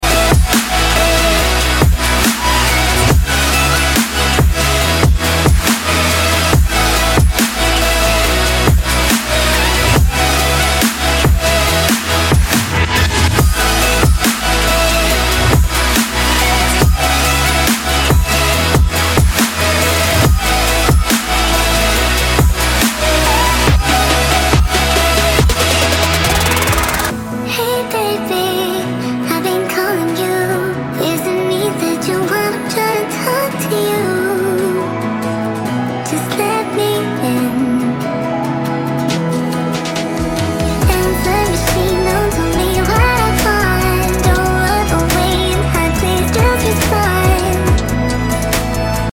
melodicbass